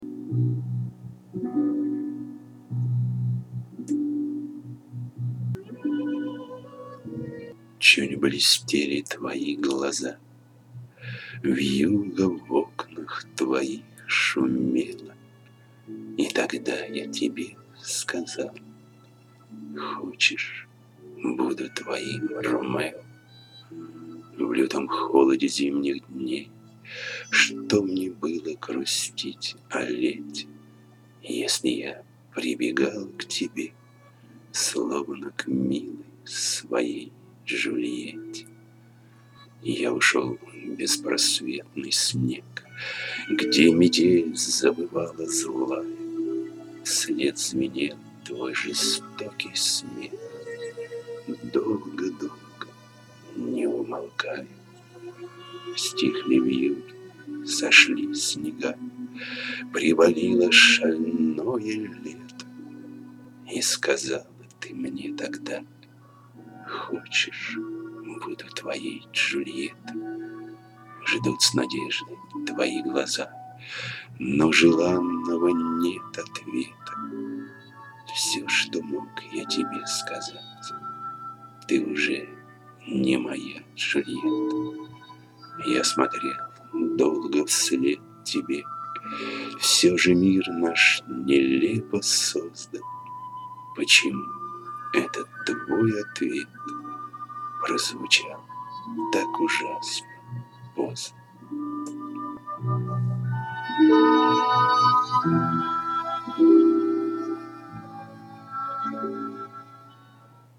Прекрасная дворовая песня.